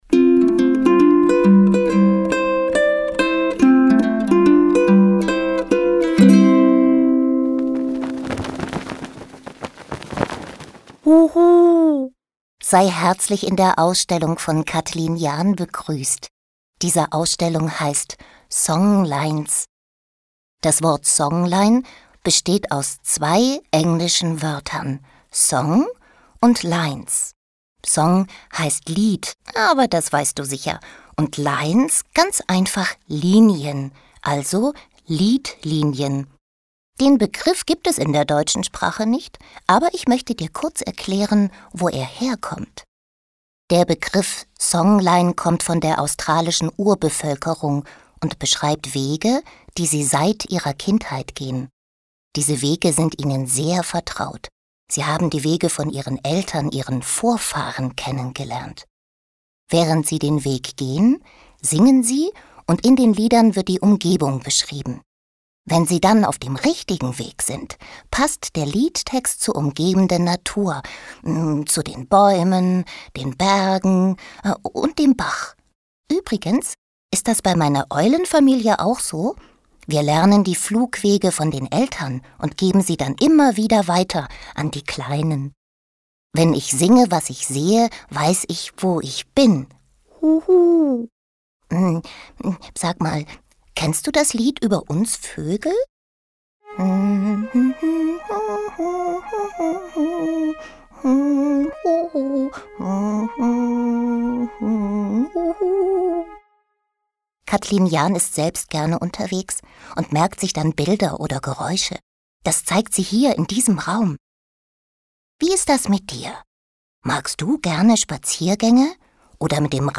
Willkommen bei den Songlines - Audioguide für Kinder
audioguide-kinder-02-willkommen-bei-den-songlines.mp3